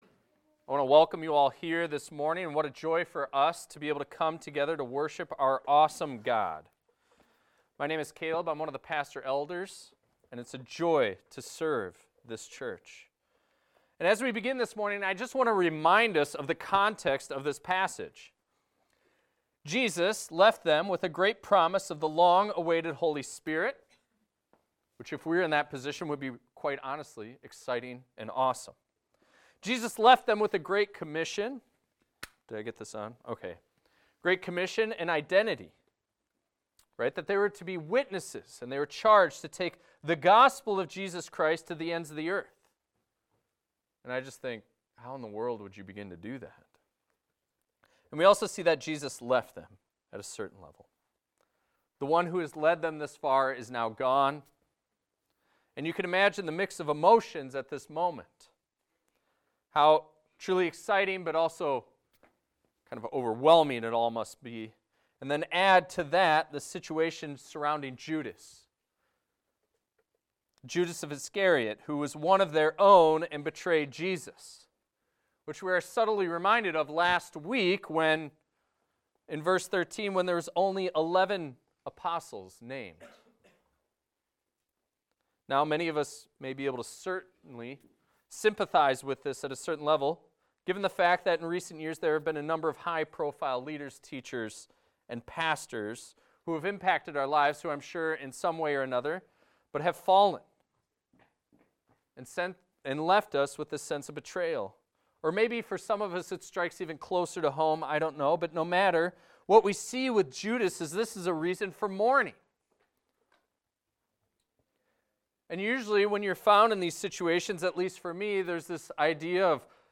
This is a recording of a sermon titled, "A Faithful Replacement."